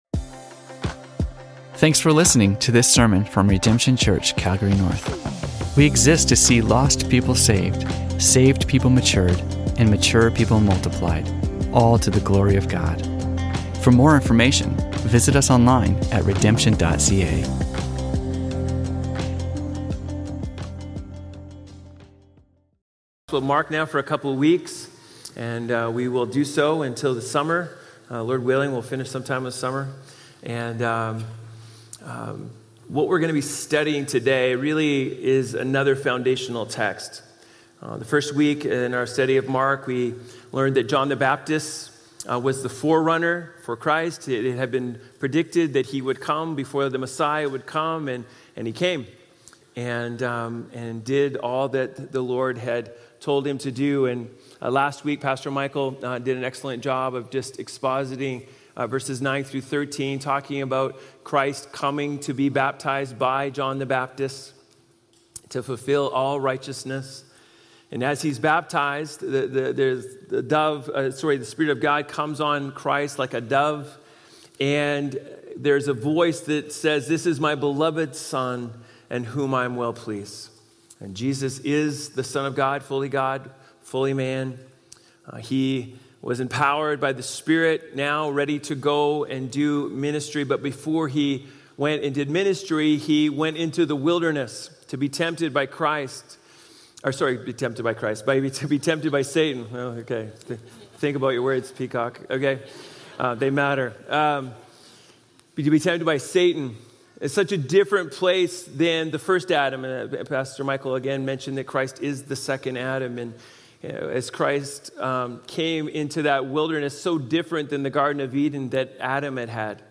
Religion Christianity Harvest Sermons Calgary Canada Redemption Church Calgary North Redemption Church Content provided by Redemption Church Calgary North and Redemption Church.